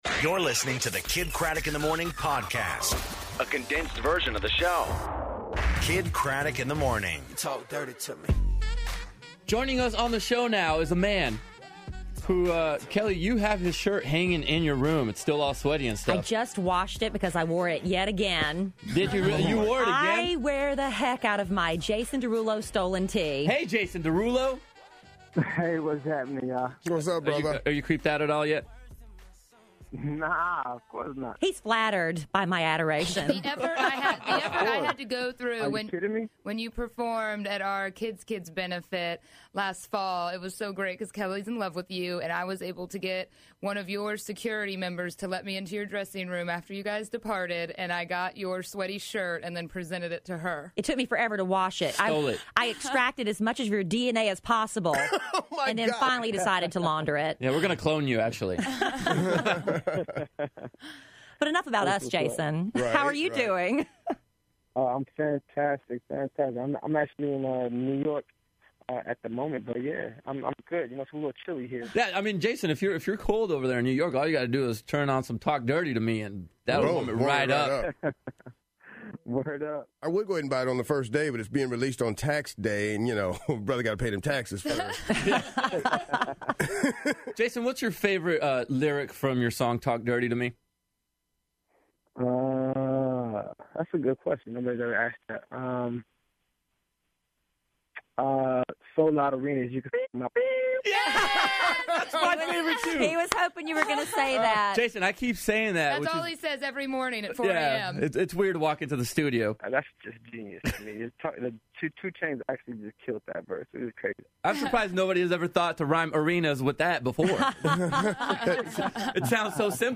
Jason Derulo On The Phone, Judge Lynn Toler, And Open Lines